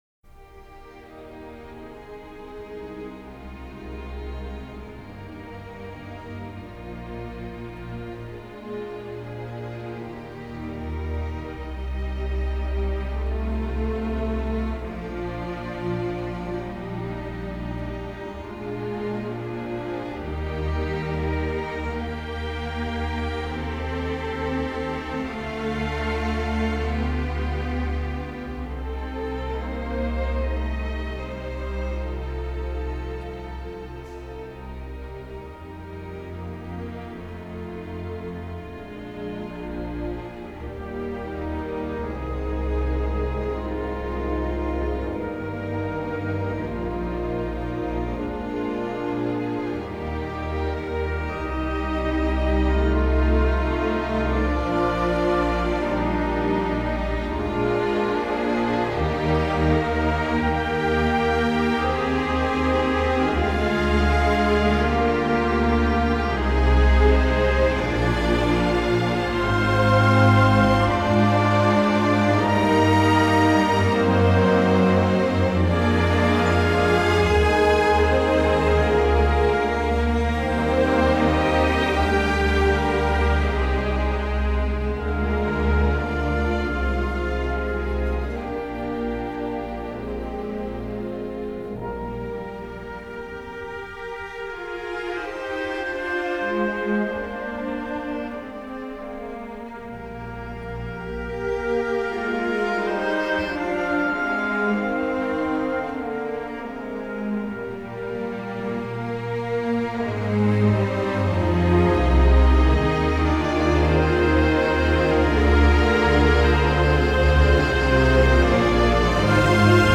The soft and enchanting melody swept me away while Kenneth Branagh recited the “Be Not Afeard” speech from Shakespeare’s “The Tempest”.
I suppose that’s why this composition evokes in me feelings of happiness, satisfaction and gratitude.